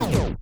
scratch08.wav